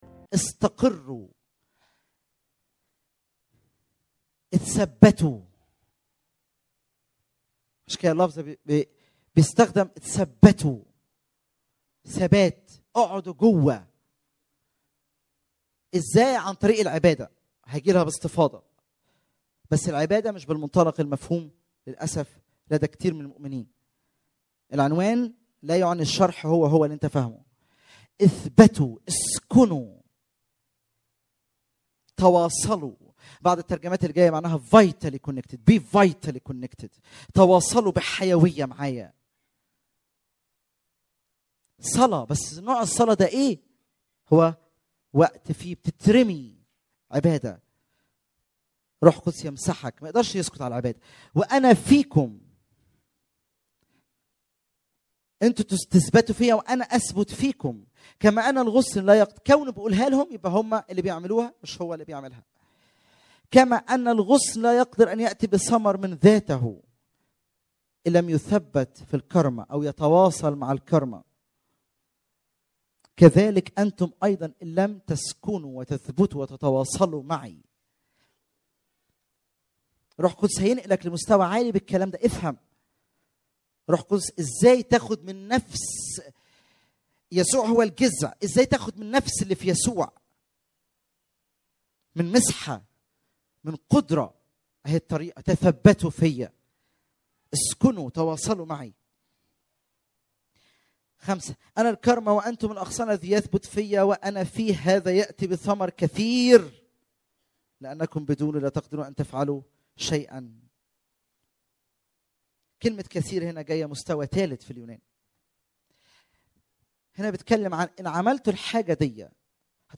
* مؤتمر نوفمبر 2013 عن شركة الروح القدس